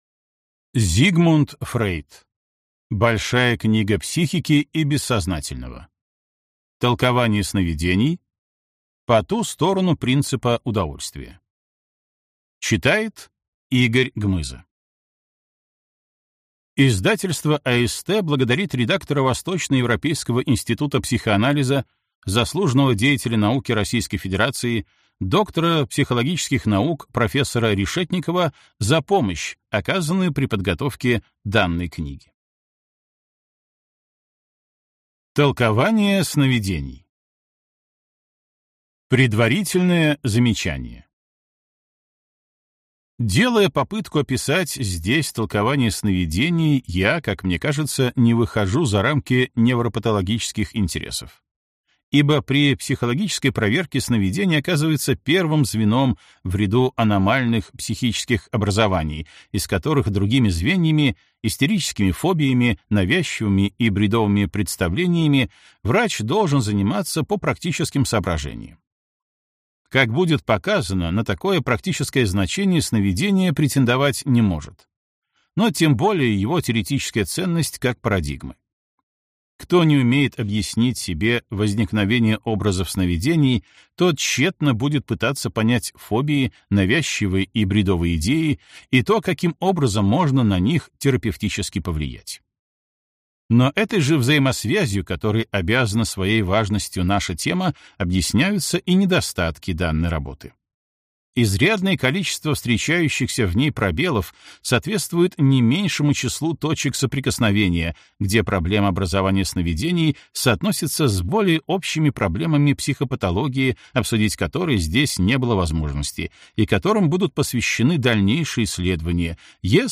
Аудиокнига Большая книга психики и бессознательного. Толкование сновидений. По ту сторону принципа удовольствия | Библиотека аудиокниг